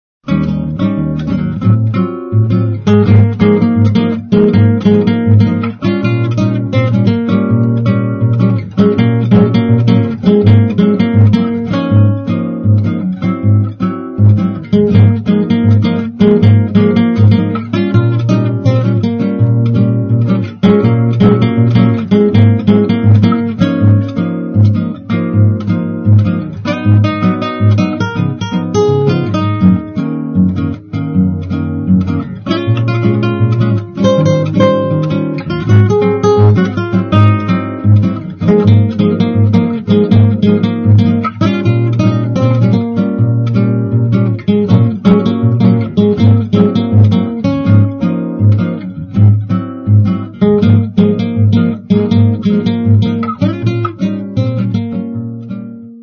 SPAZIO BOSSANOVA